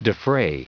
Prononciation du mot defray en anglais (fichier audio)
Prononciation du mot : defray